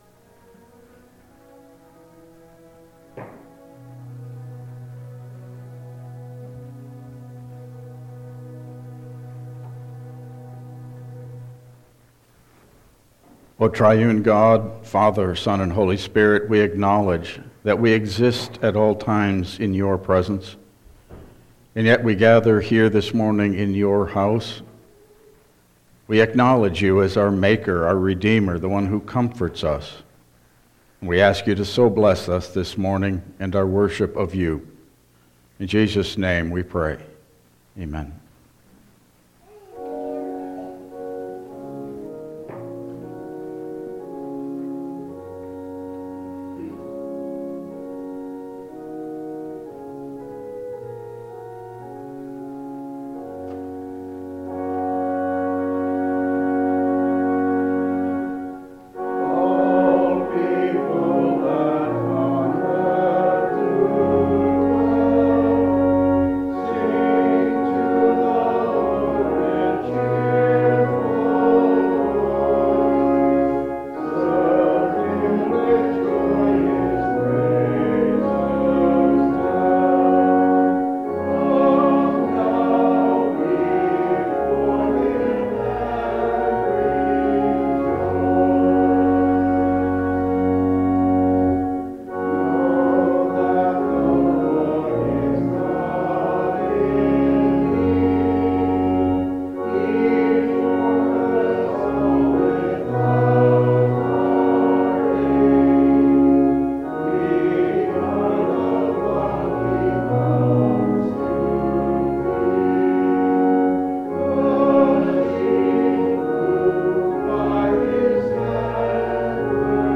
Service Type: Regular Service